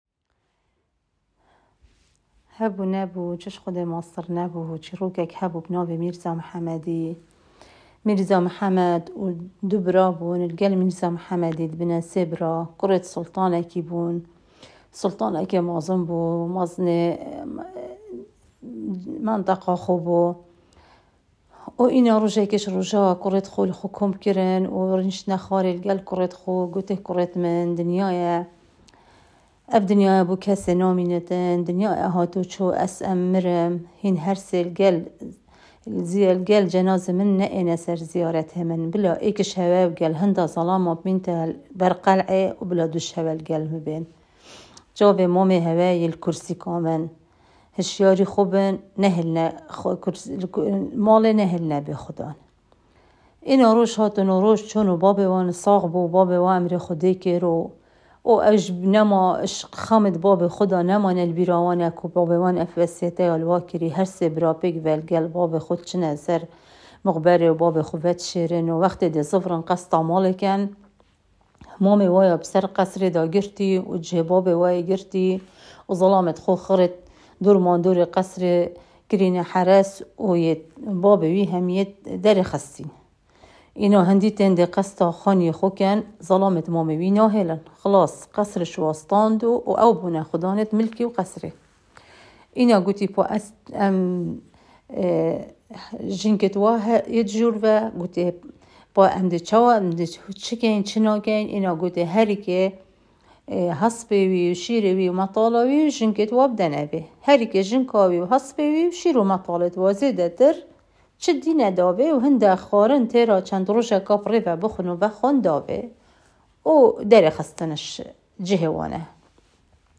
Duhok_NK-_Mirzas_Adventures.m4a